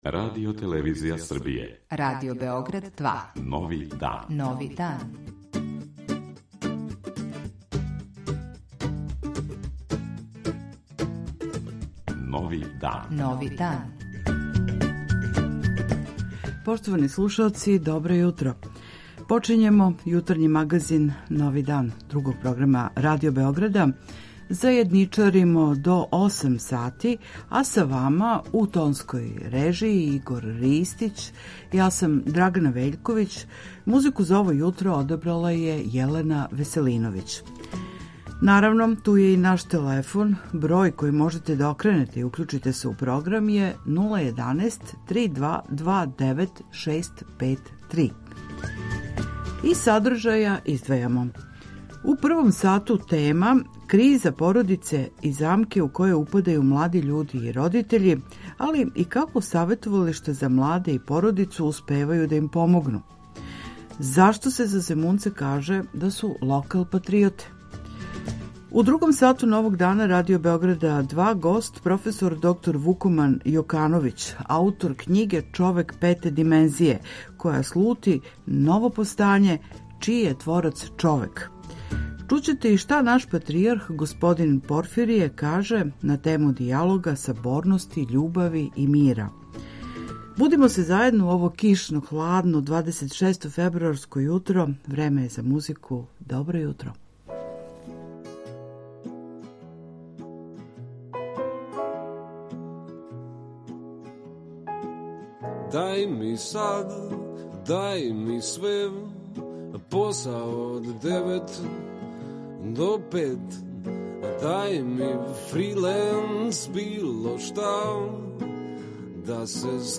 Зашто је дијалог и међусобна комуникација људи пресудна за нашу будућност, - у беседи на трибини „Четвртком на Машинцу", објашњава Патријарх српски господин Порфирије. 80 година је протекло од Игманског марша, још једне страшне епопеје нашег народа у Другом светском рату.